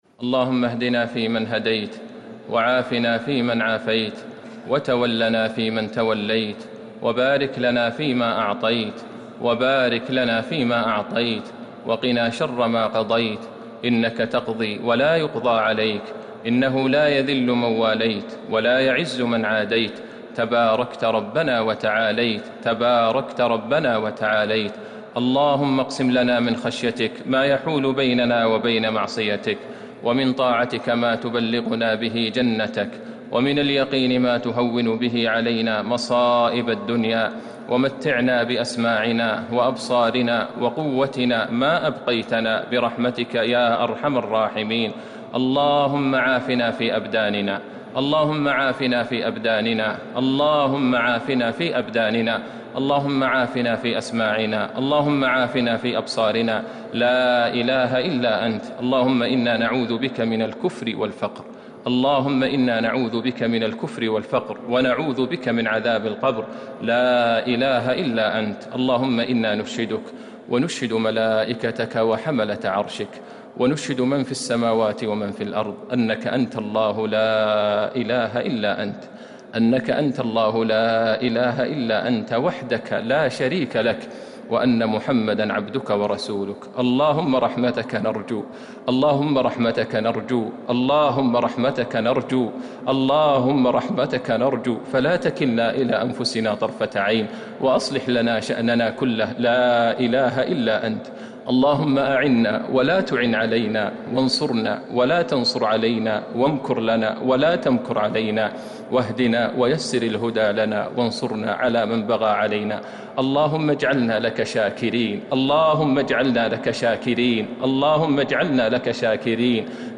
دعاء القنوت ليلة 14 رمضان 1441هـ > تراويح الحرم النبوي عام 1441 🕌 > التراويح - تلاوات الحرمين